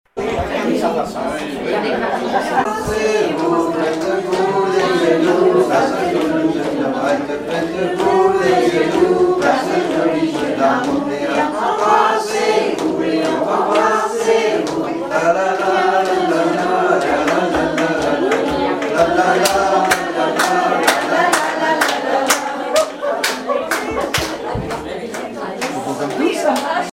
Club d'anciens de Saint-Pierre association
Rondes enfantines à baisers ou mariages
ronde à embrasser
Pièce musicale inédite